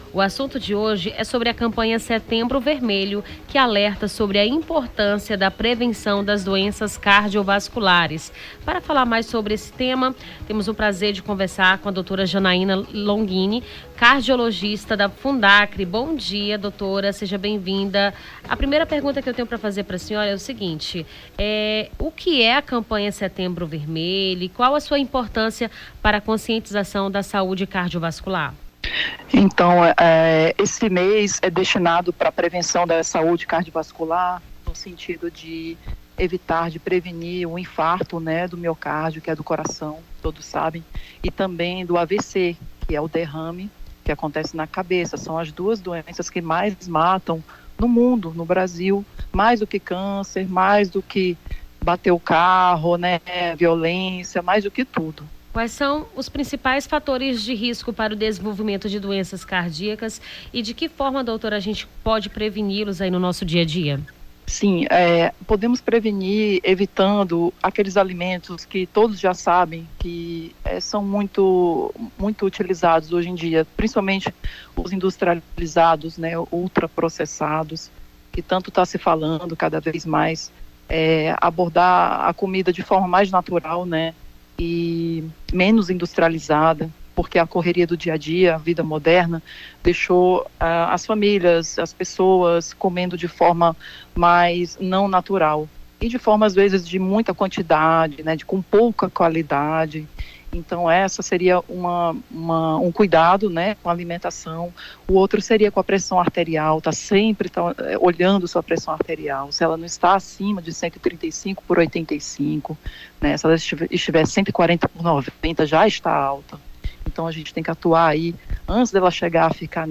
Nome do Artista - CENSURA - ENTREVISTA SETEMBRO VERMELHO (16-09-24).mp3